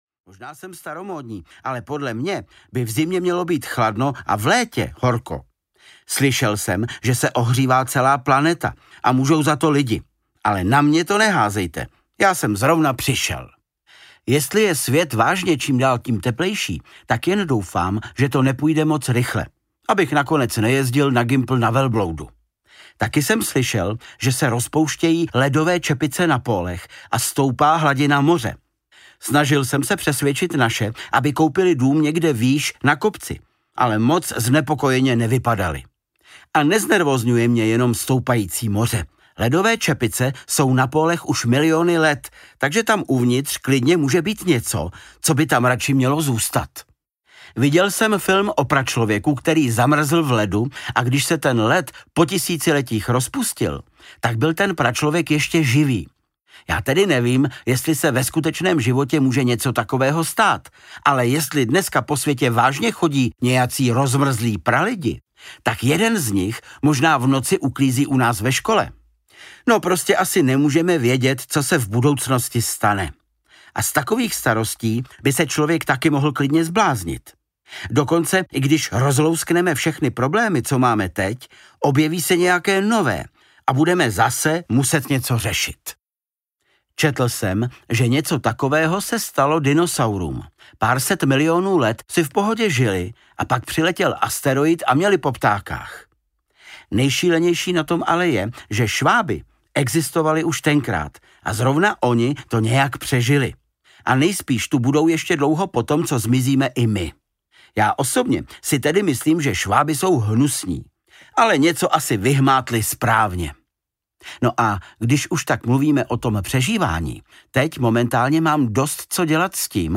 Ukázka z knihy
• InterpretVáclav Kopta